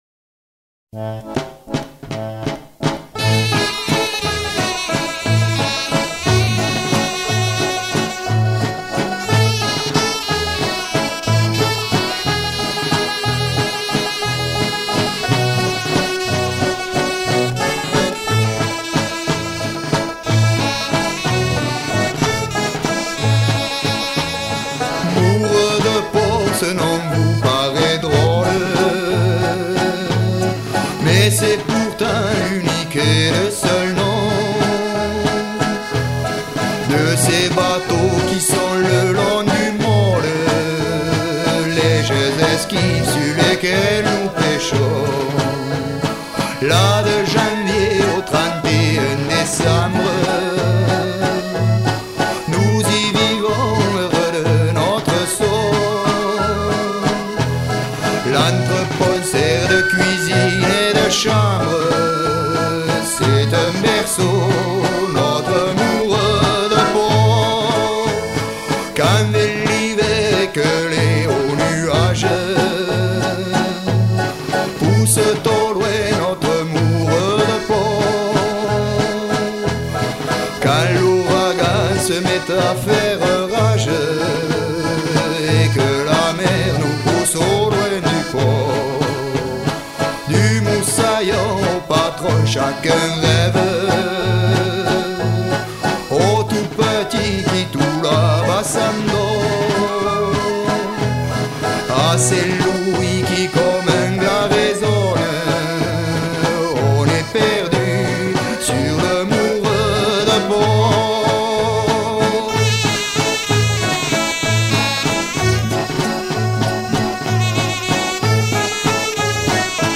danse : valse
Genre strophique
Pièce musicale éditée